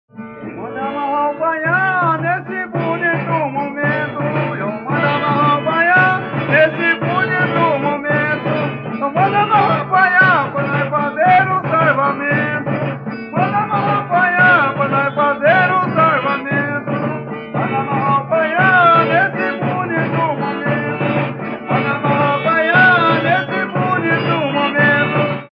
Dança de conjunto com formação em fileiras, em geral chamadas de linhas. O mestre é a primeira figura de sua linha, e o contramestre, da outra linha, à qual geralmente pertencem os que fazem o contracanto.
Colocando os bastões cruzados no chão, os dançarinos cantam enquanto dançam.
autor: Moçambique de Aparecida, data: 1975